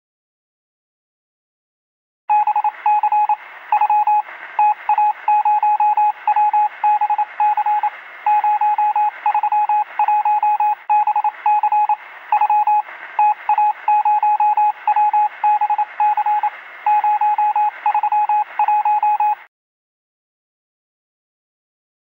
جلوه های صوتی
دانلود صدای کد مرس از ساعد نیوز با لینک مستقیم و کیفیت بالا
برچسب: دانلود آهنگ های افکت صوتی اشیاء